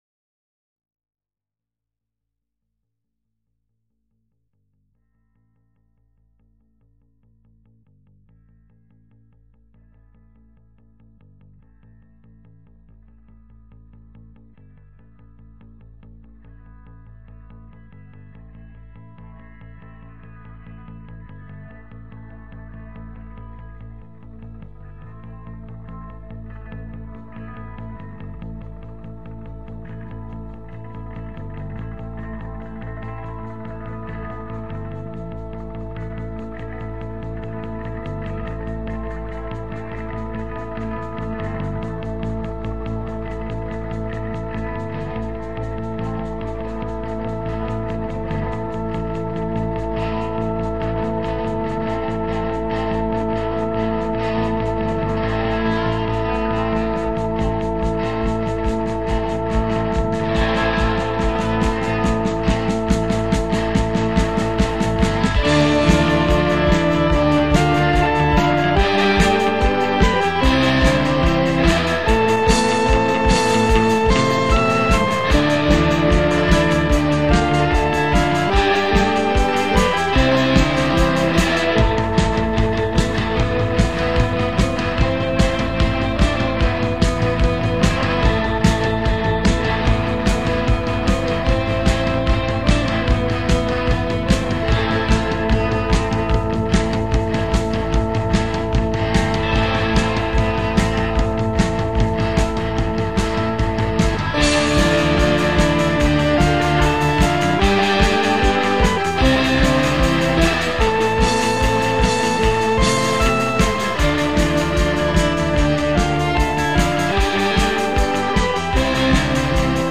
Guitar, Voice
Drums
Bass
Organ, Synthesizer